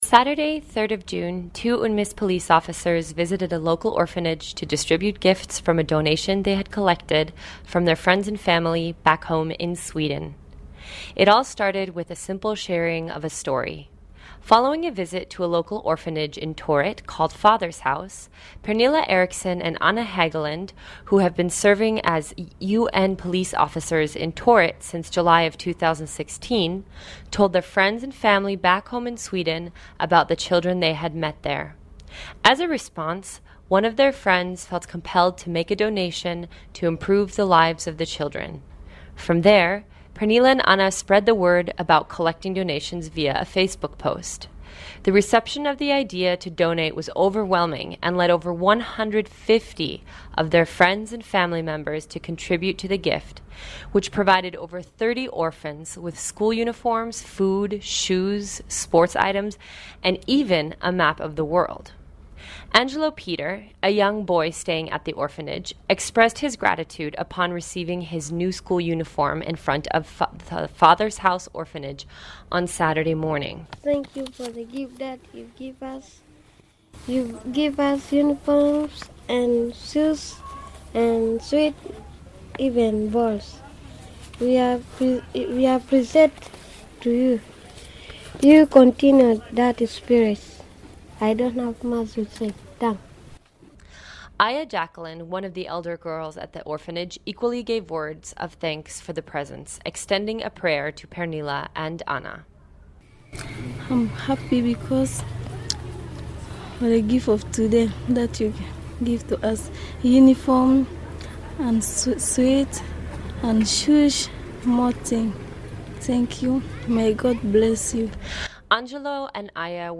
On Saturday, the UNPOL officers visited the center and donated some items that visibly brought wide smiles to the children. Here is a report